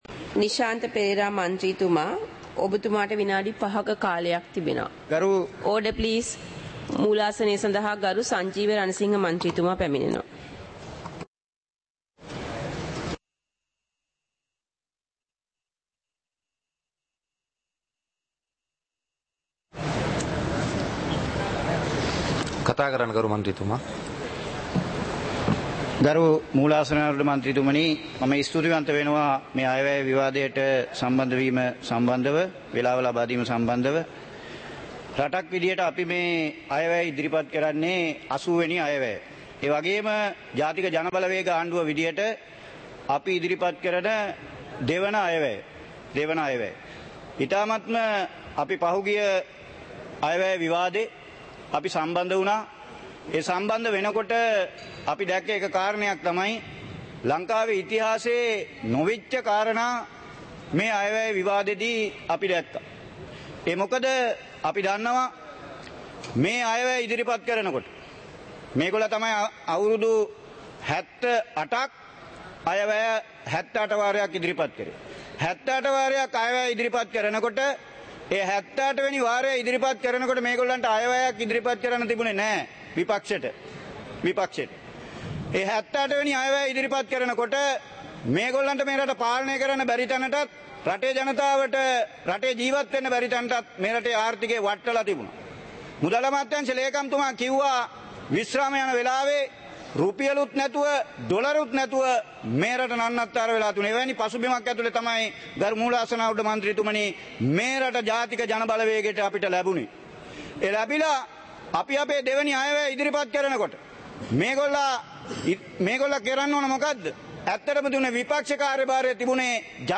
சபை நடவடிக்கைமுறை (2025-12-05)
பாராளுமன்ற நடப்பு - பதிவுருத்தப்பட்ட